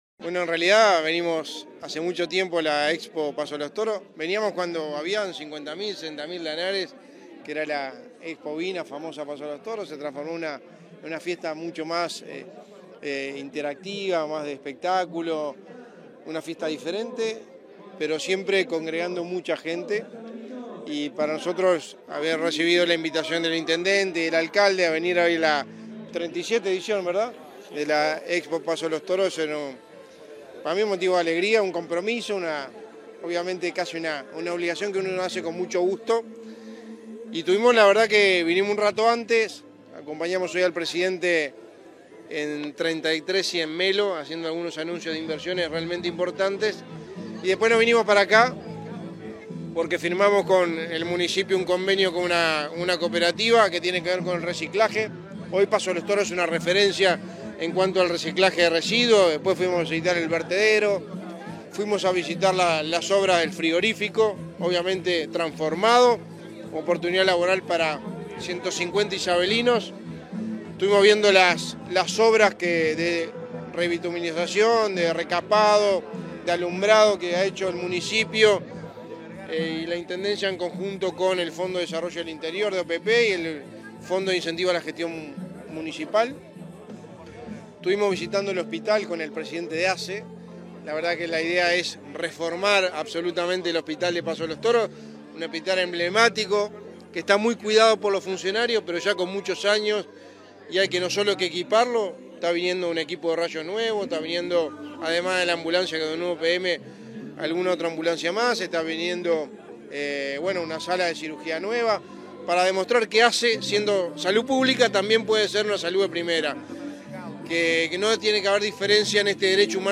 Declaraciones del secretario de la Presidencia, Álvaro Delgado, tras visitar Expo Feria
Tras participar en la 37.ª Expo Feria de Paso de los Toros, este 9 de febrero, el secretario de la Presidencia, Álvaro Delgado, realizó declaraciones